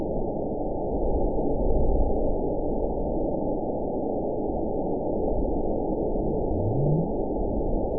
event 919821 date 01/25/24 time 09:55:22 GMT (1 year, 10 months ago) score 8.98 location TSS-AB01 detected by nrw target species NRW annotations +NRW Spectrogram: Frequency (kHz) vs. Time (s) audio not available .wav